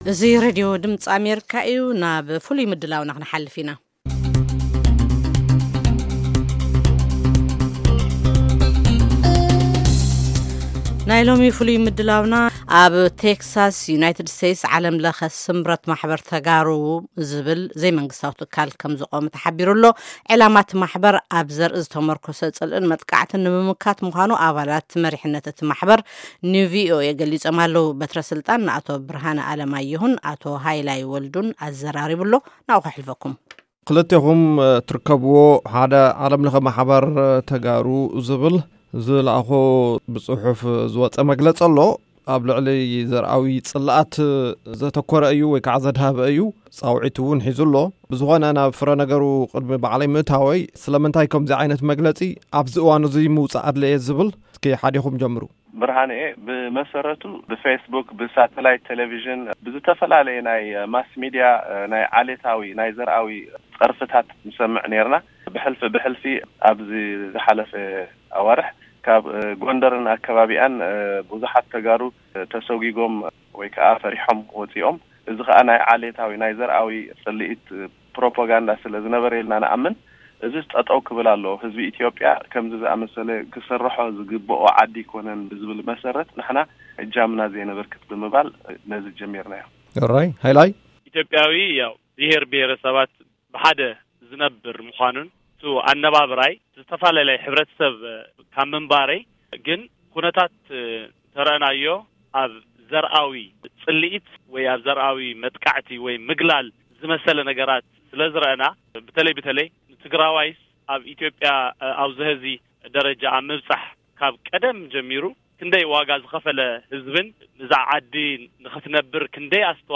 ቃከ ምልልስ ምስ መሪሕነት ዓለም ለኸ ማሕበር ተጋሩ